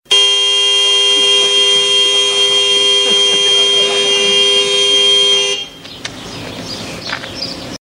Original creative-commons licensed sounds for DJ's and music producers, recorded with high quality studio microphones.
LOUD HORN BLARING.mp3 .WAV .MP3 .OGG 0:00 / 0:08 train close up electric in the mountains with horn.wav .WAV .MP3 .OGG 0:00 / 0:15 recorded with a DR 40 - Tascam, near a small train station.
loud_horn_blaring_1eo.wav